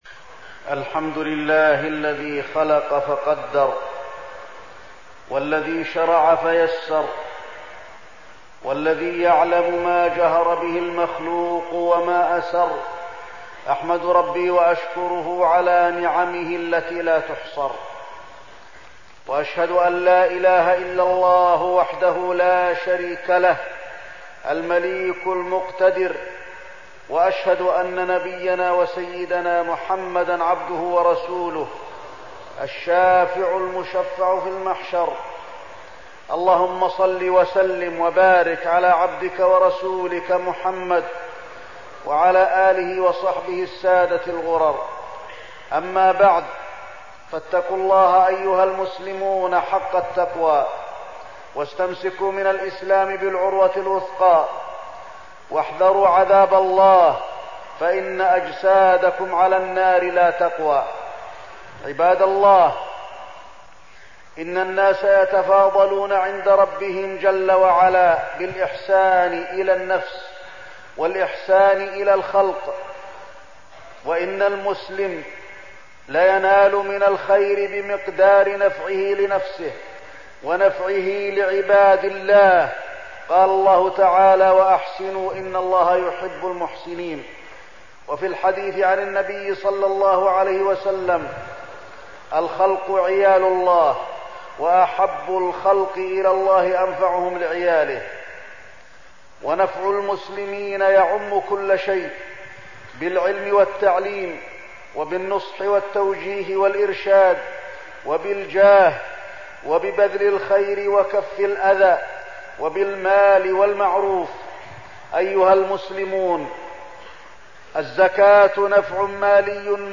تاريخ النشر ٢١ شعبان ١٤١٦ هـ المكان: المسجد النبوي الشيخ: فضيلة الشيخ د. علي بن عبدالرحمن الحذيفي فضيلة الشيخ د. علي بن عبدالرحمن الحذيفي الزكاة